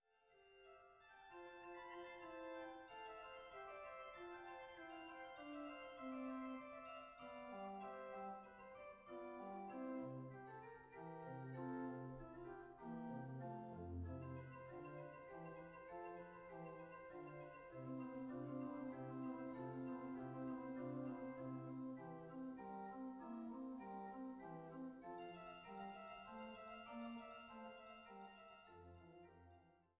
Schramm-Orgel der Stadtkirche St. Otto zu Wechselburg
B-Dur: Vivace - Adagio - Vivace